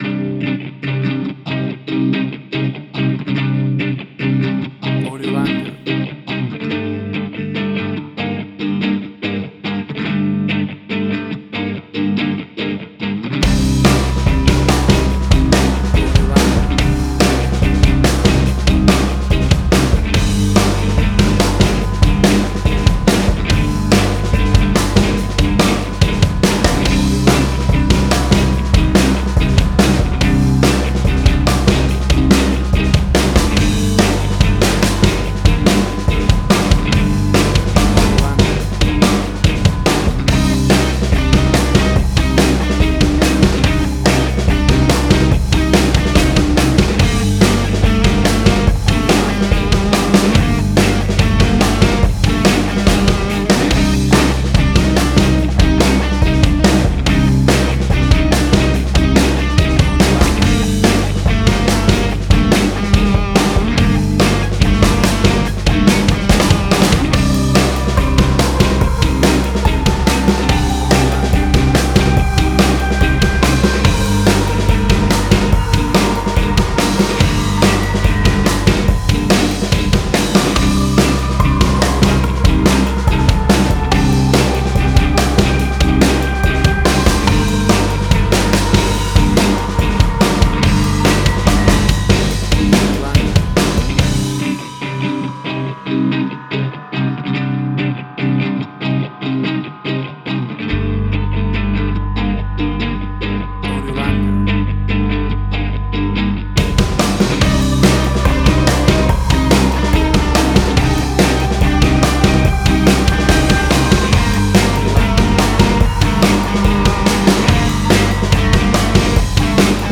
Hard Rock
Heavy Metal.
Tempo (BPM): 144